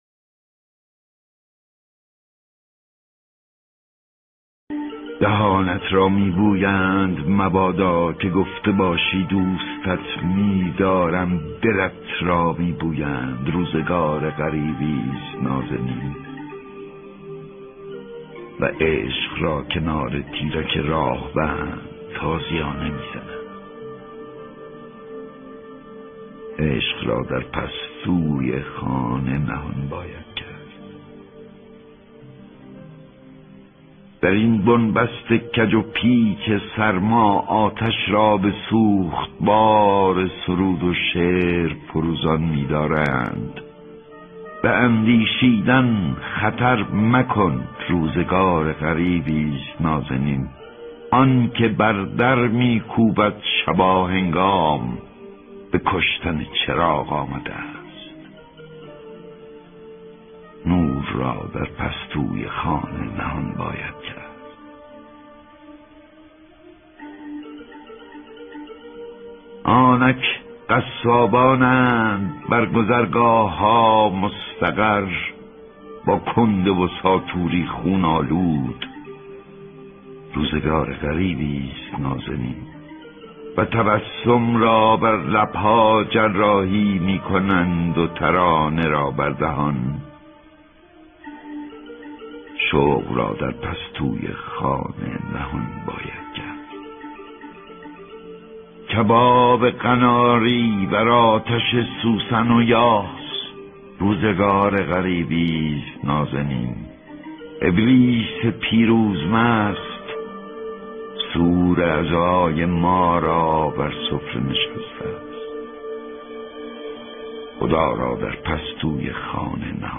دانلود دکلمه دهانت را می بویند با صدای احمد شاملو
گوینده :   [احمد شاملو]